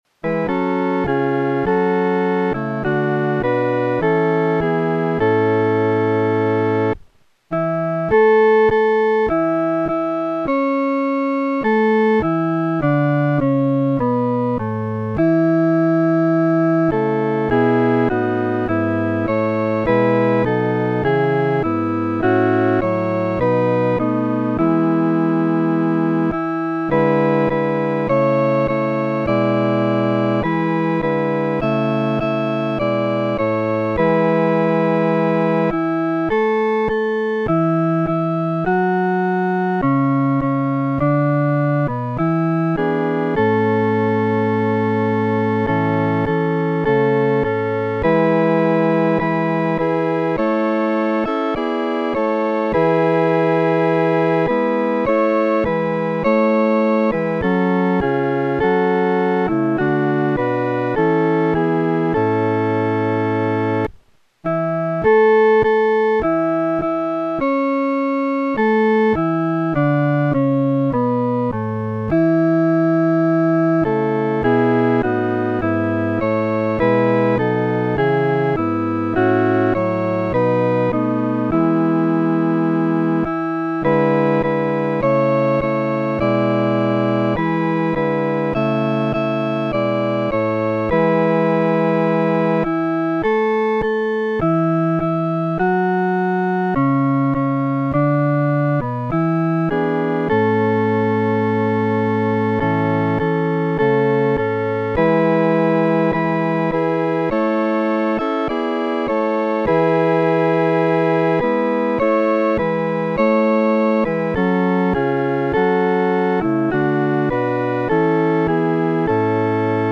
合唱
四声
本首圣诗由网上圣诗班 (青草地）录制